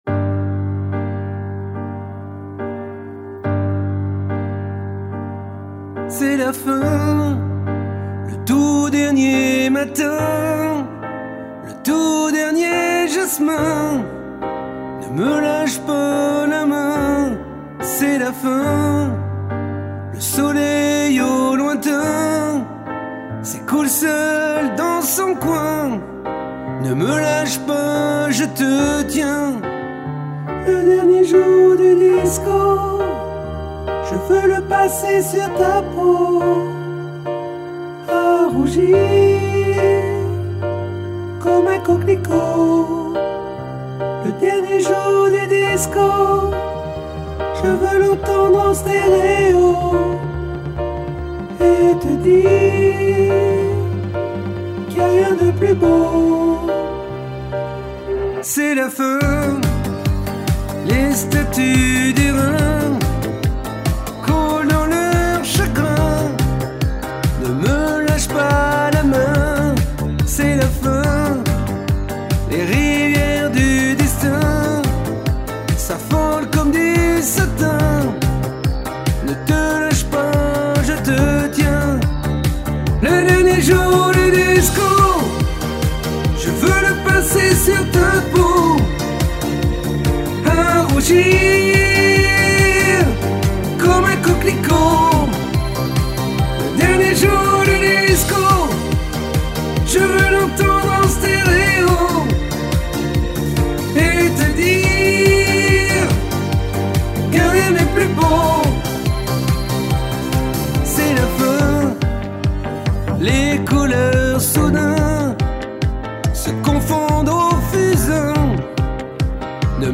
Alto 01